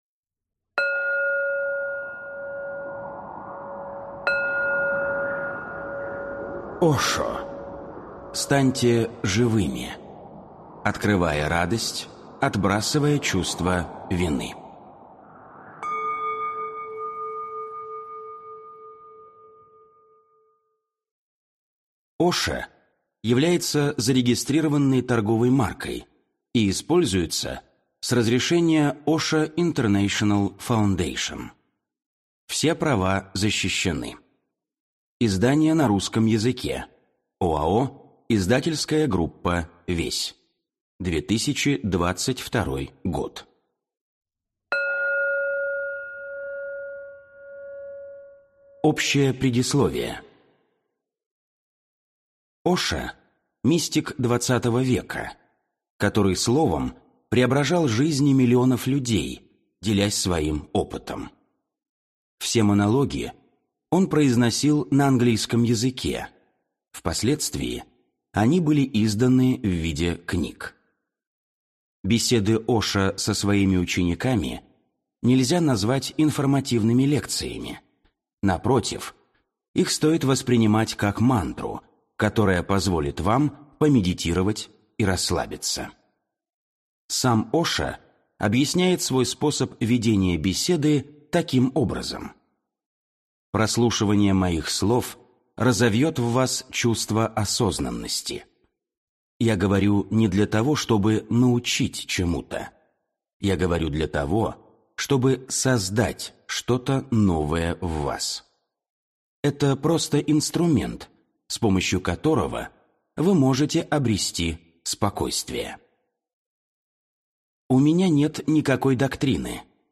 Аудиокнига Станьте живыми. Открывая радость, отбрасывая чувство вины | Библиотека аудиокниг